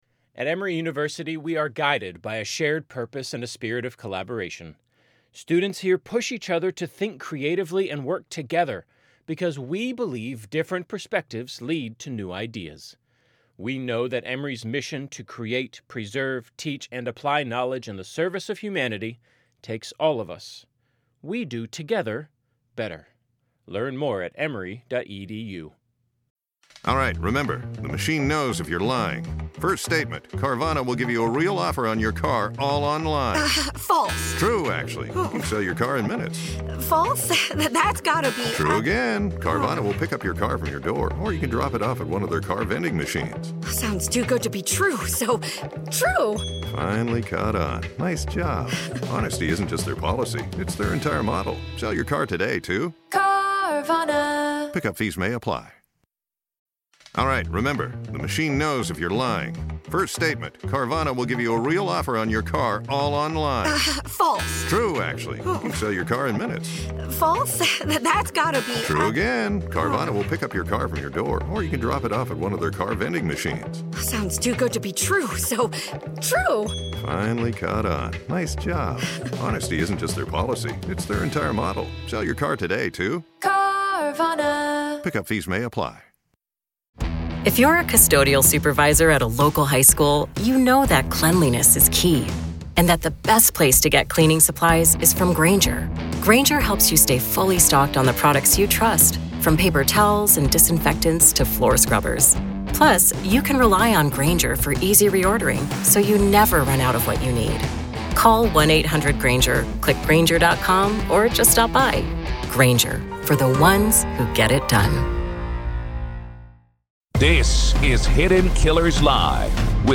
FBI BREAKS SILENCE: Explosive Press Conference on Charlie Kirk Assassination
This is the press conference that shook the nation.